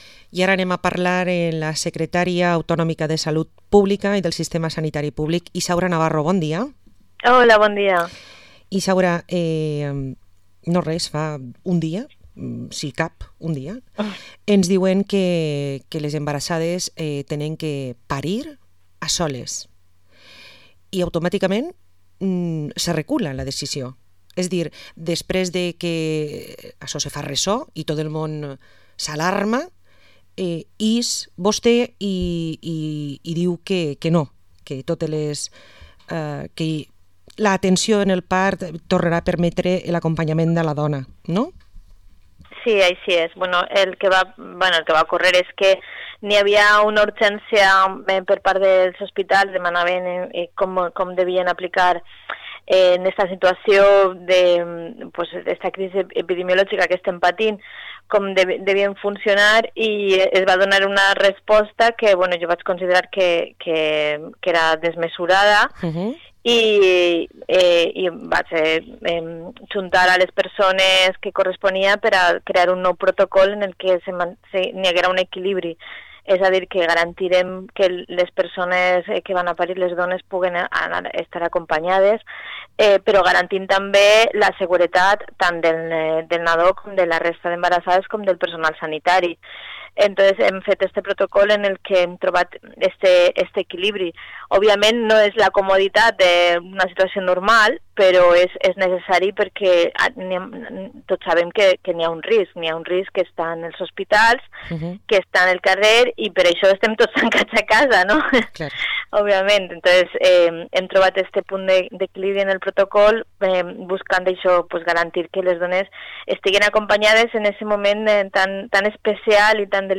Entrevista a la secretaría autonómica de Salud Pública y del sistema Sanitario Público, Isaura Navarro